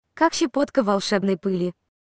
• Категория: Голосовой помощник Siri
• Качество: Высокое